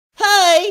mii-mii-hi-sound-effect.mp3